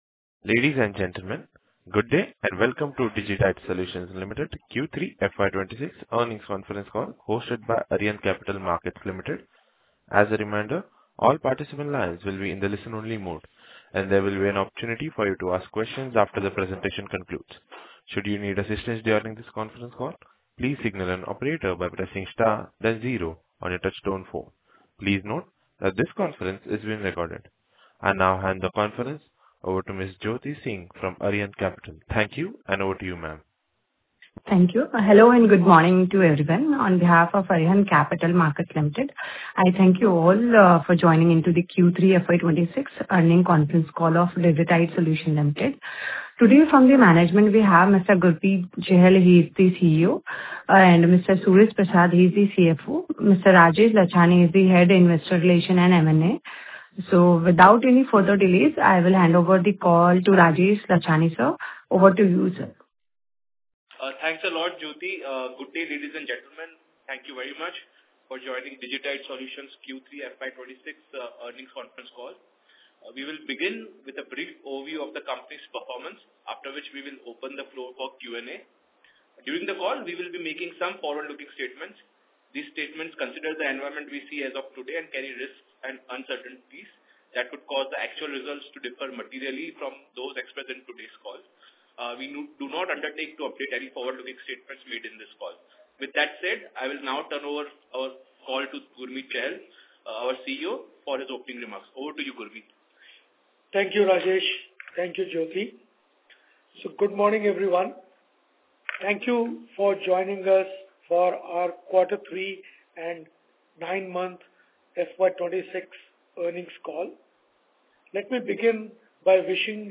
Concalls
Digitide-Q3FY26-Earnings-Call.mp3